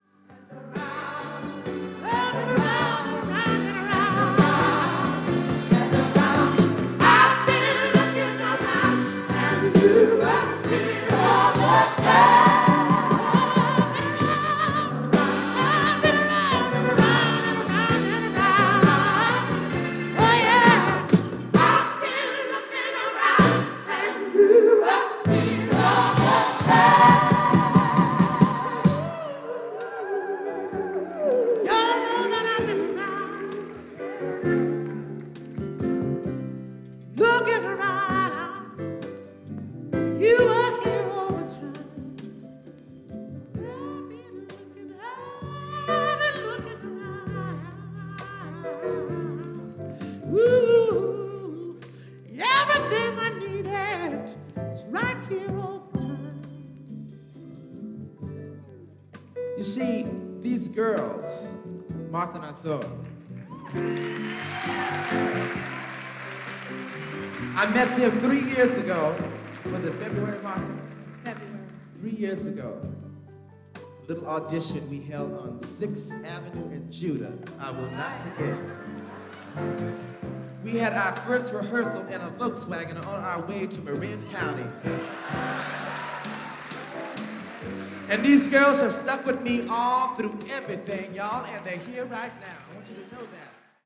San Francisco・War Memorial Opera House での２枚組ライブ盤です。